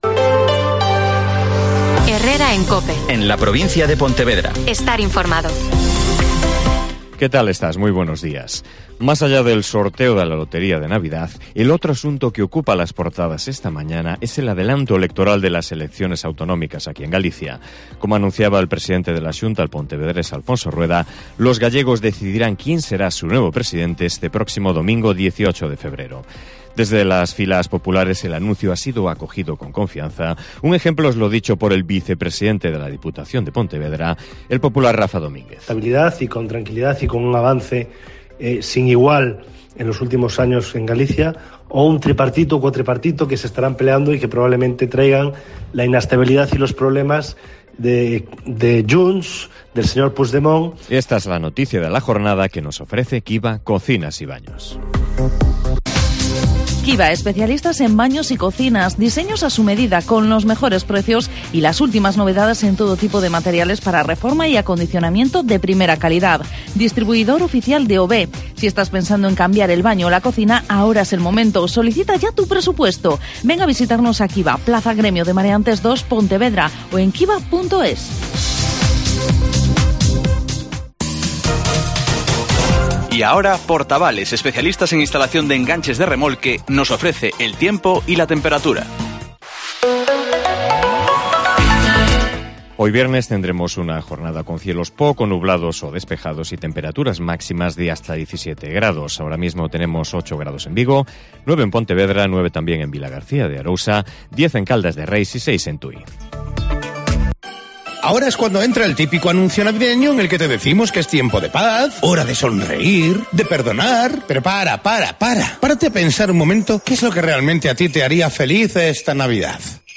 Herrera en COPE Pontevedra y COPE Ría de Arosa (Informativo 08:30h)